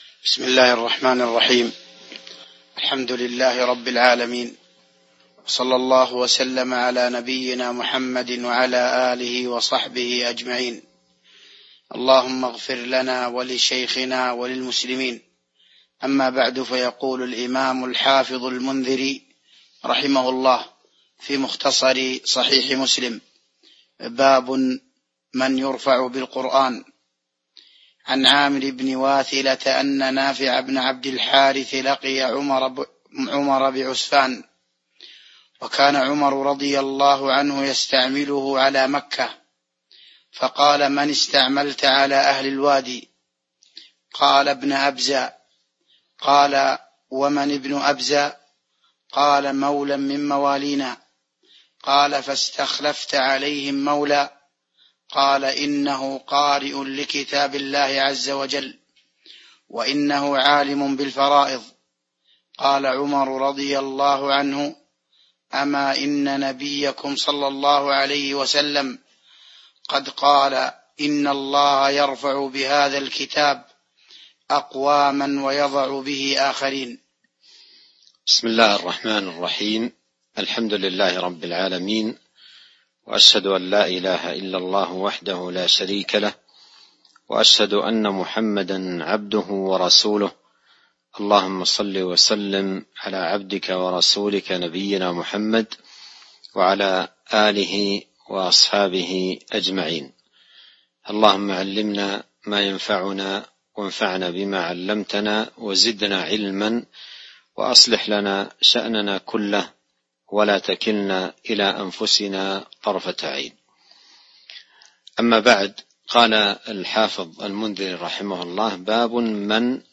تاريخ النشر ٨ رمضان ١٤٤٢ هـ المكان: المسجد النبوي الشيخ: فضيلة الشيخ عبد الرزاق بن عبد المحسن البدر فضيلة الشيخ عبد الرزاق بن عبد المحسن البدر باب من يرفع القرآن (08)